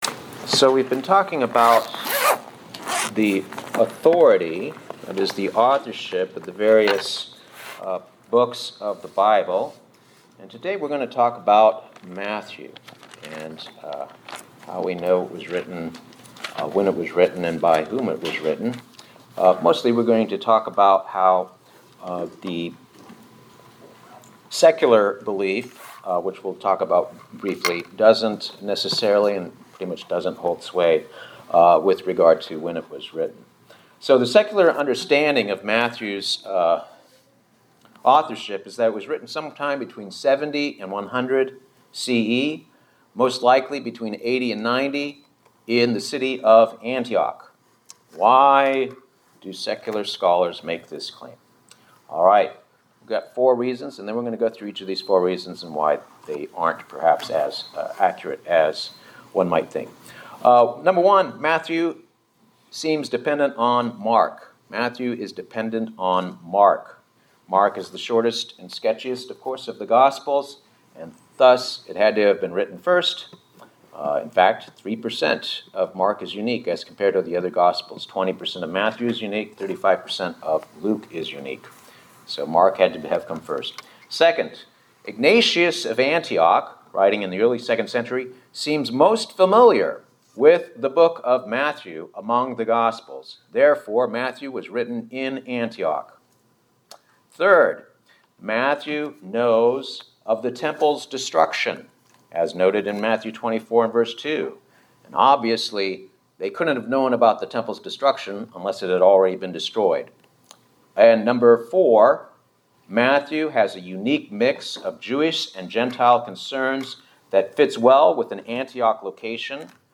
Sermons
Given in Buford, GA